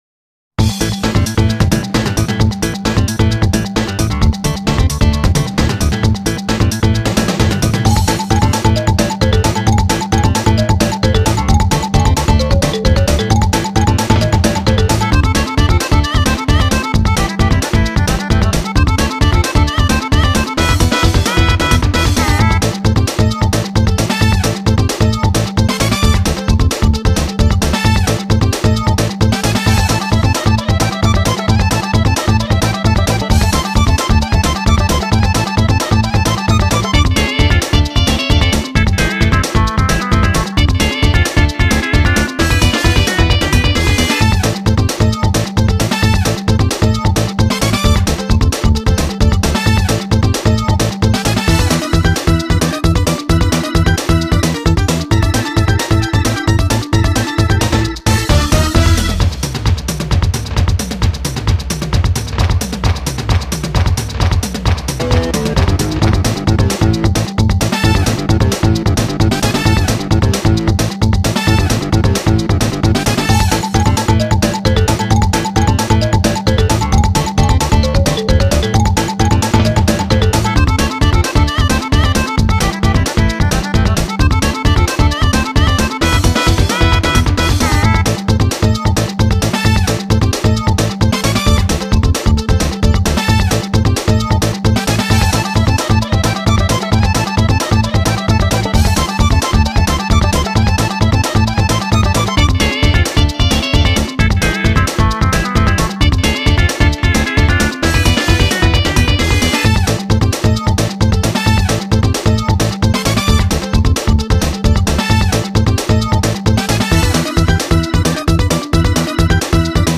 BPM132
Audio QualityCut From Video